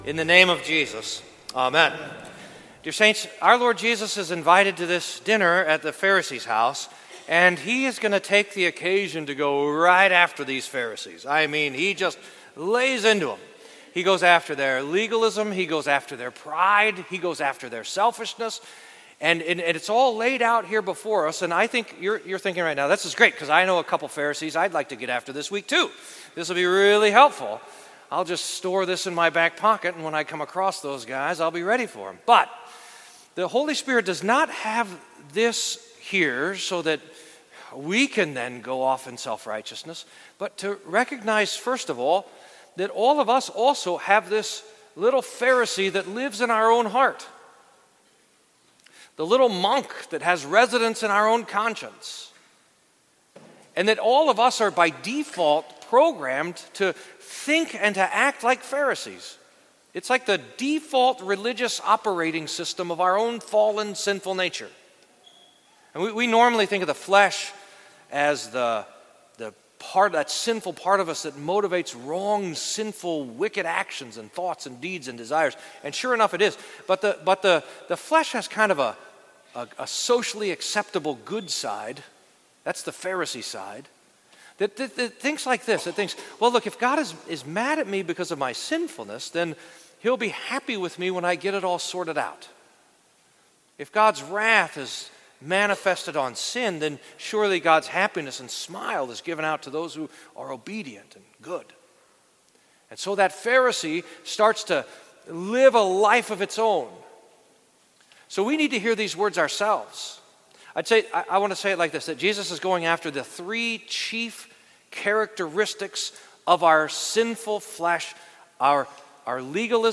St Paul Lutheran Church Podcasts